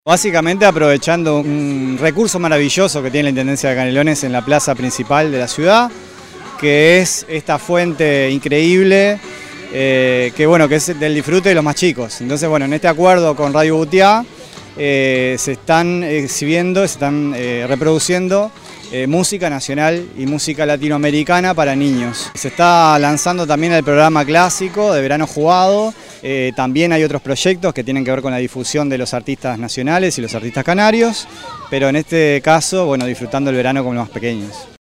sergio_machin_director_general_de_cultura_6.mp3